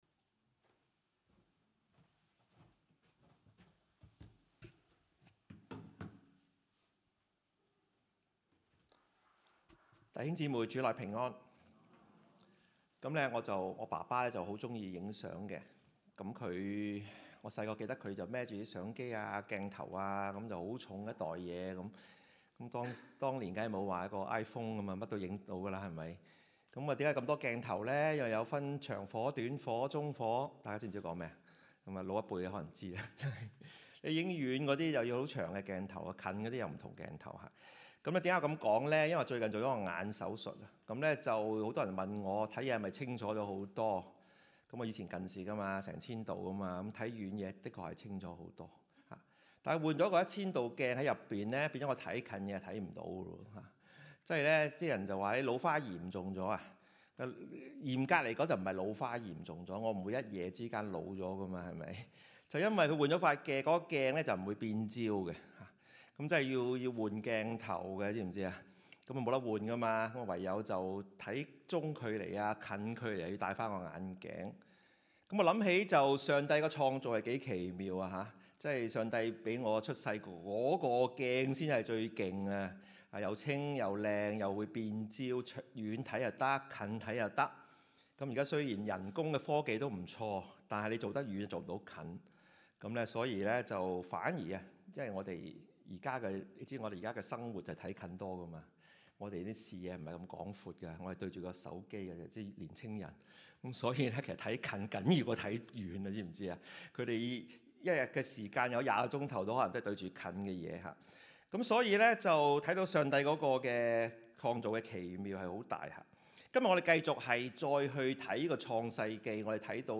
啟示錄 3:14-21 Service Type: 粵語崇拜